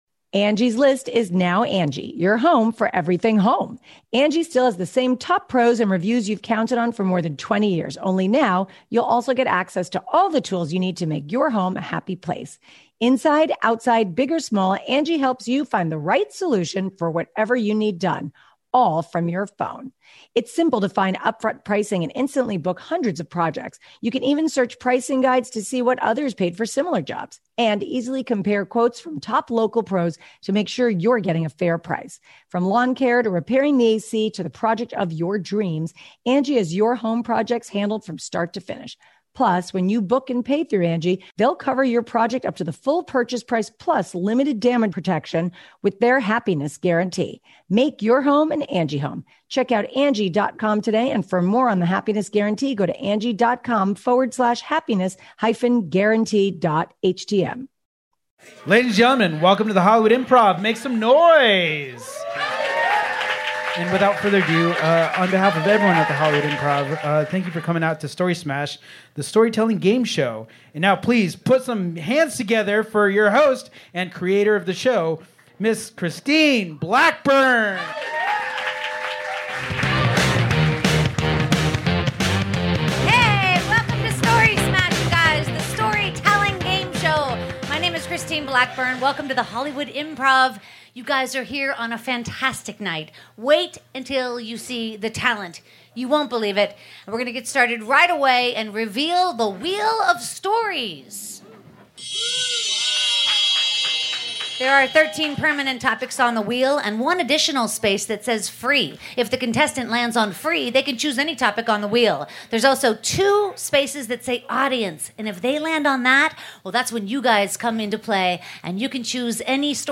499 - Story Smash the Storytelling Gameshow LIVE at The Hollywood Improv on April 28th 2018